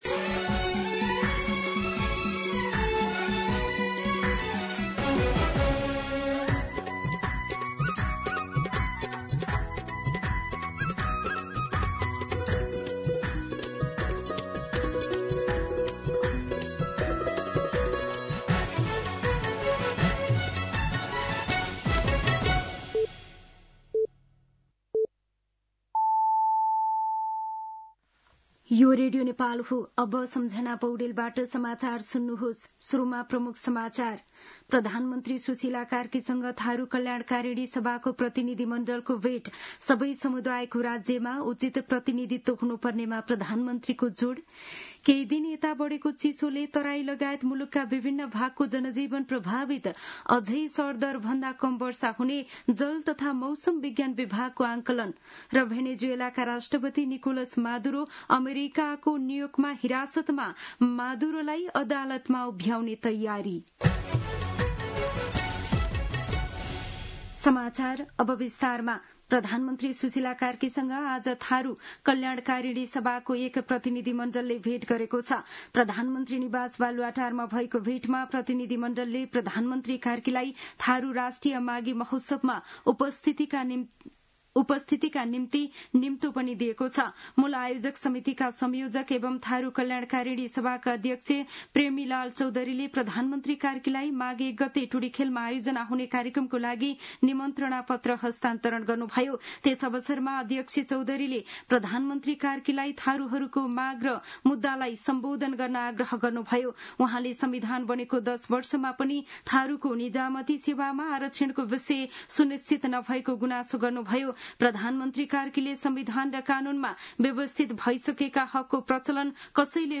दिउँसो ३ बजेको नेपाली समाचार : २० पुष , २०८२
3pm-Nepali-News.mp3